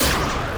Maincharactergunsound.mp3